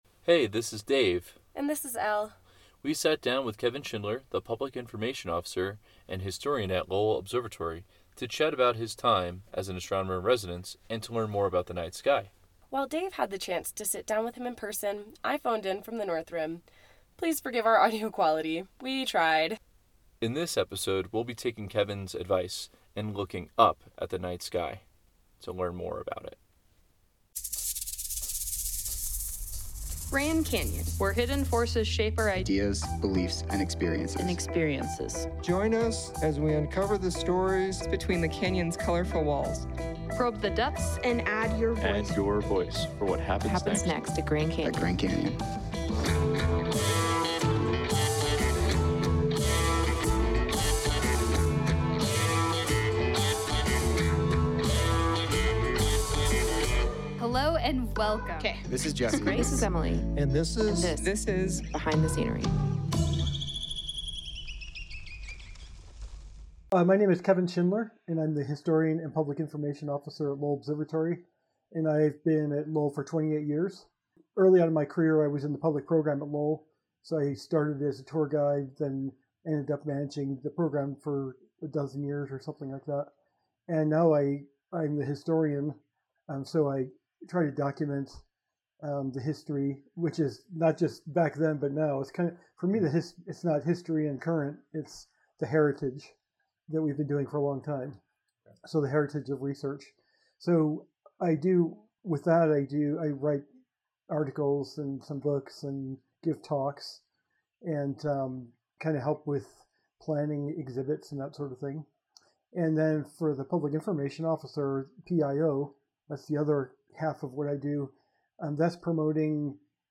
Please forgive our audio quality.